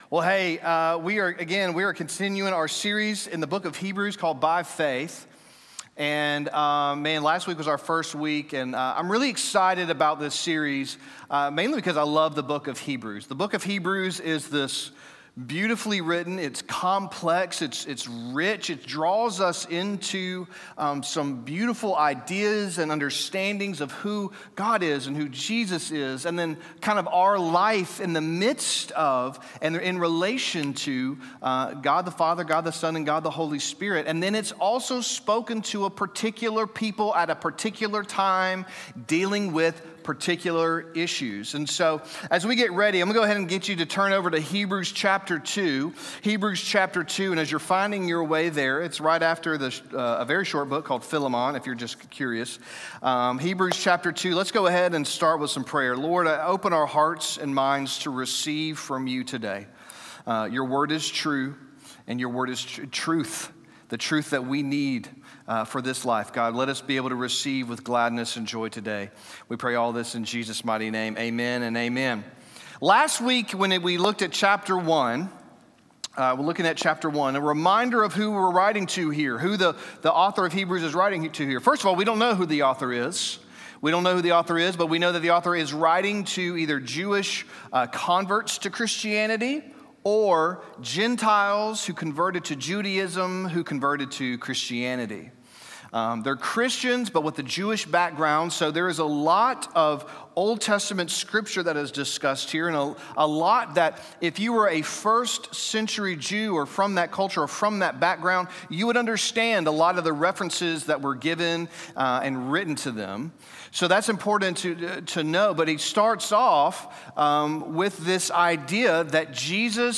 St. Andrews A_G Sunday Service __ _By Faith_ (1).mp3